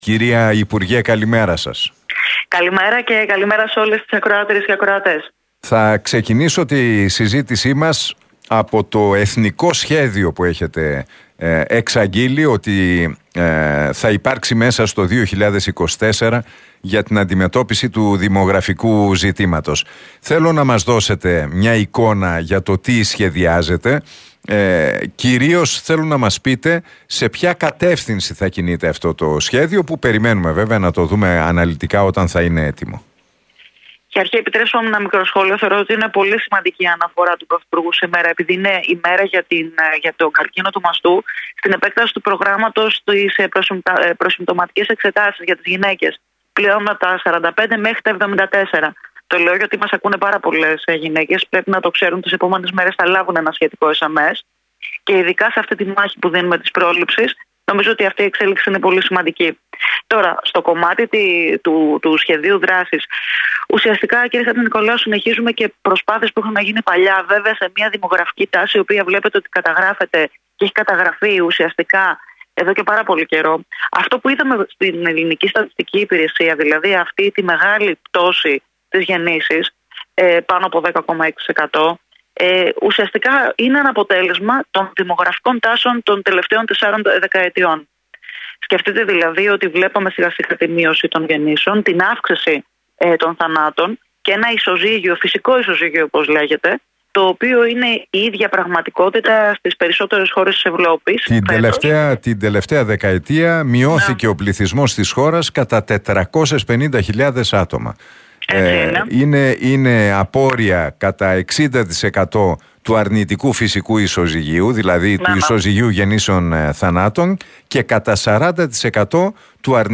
Ζαχαράκη στον Realfm 97,8: Οριζόντια δράση μεταξύ των υπουργείων για το δημογραφικό